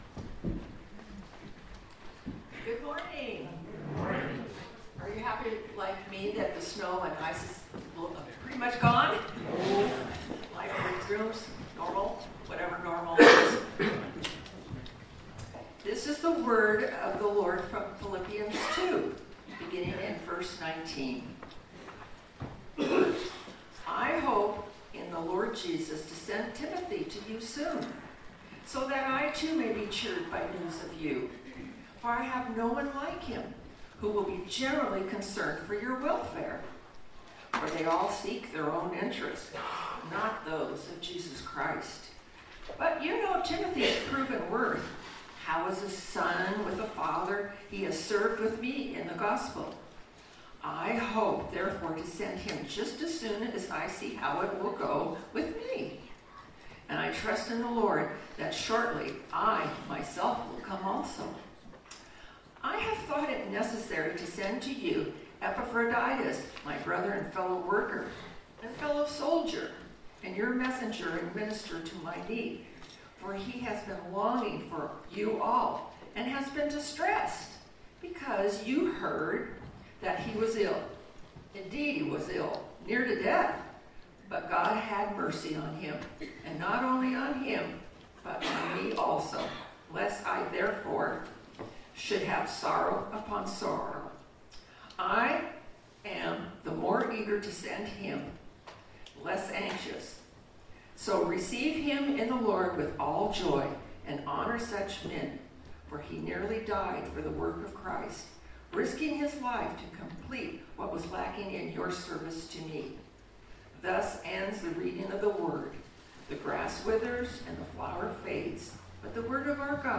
Passage: Philippians 2:19-30 Service Type: Sunday Morning